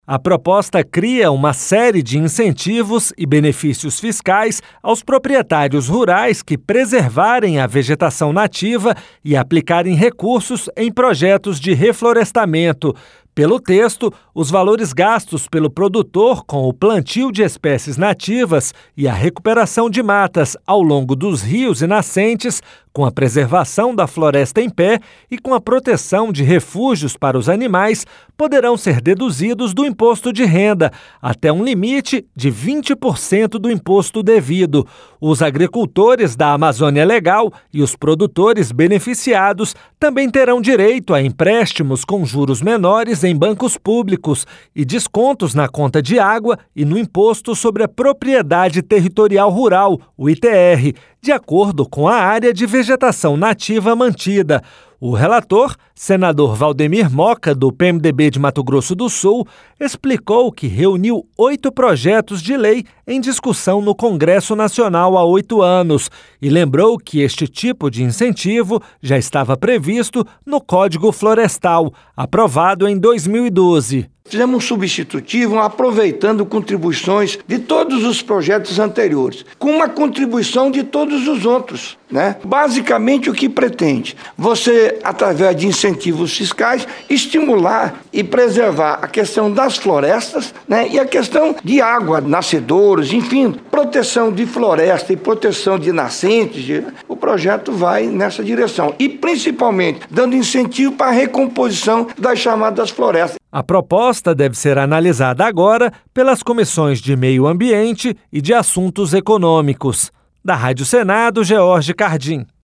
O relator, senador Waldemir Moka, do PMDB de Mato Grosso do Sul, explicou que reuniu oito projetos de lei em discussão no Congresso Nacional há oito anos e lembrou que este tipo de incentivo já estava previsto no Código Florestal, aprovado em 2012.